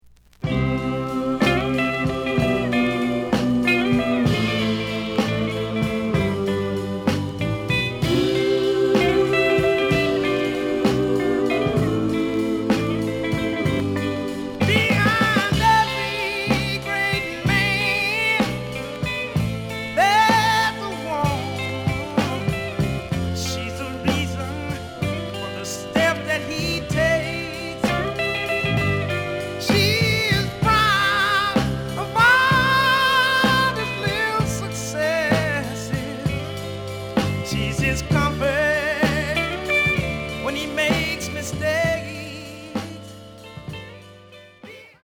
The listen sample is recorded from the actual item.
●Genre: Soul, 60's Soul